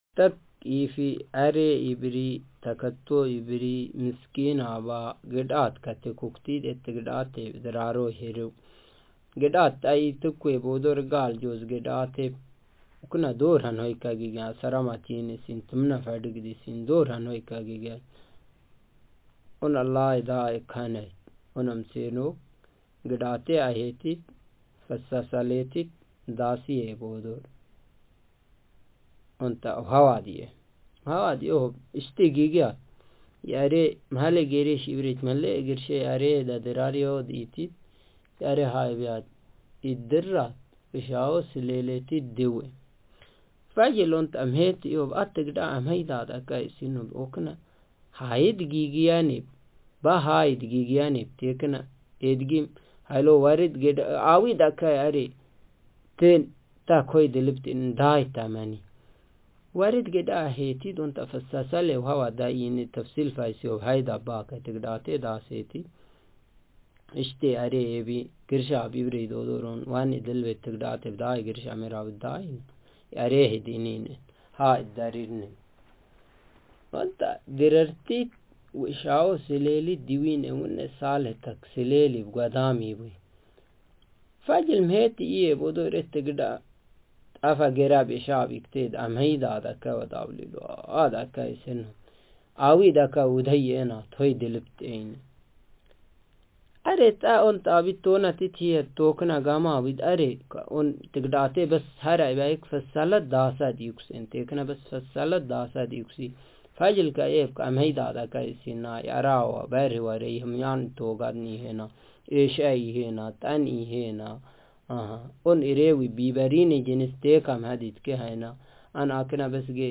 Speaker sexm
Text genretraditional narrative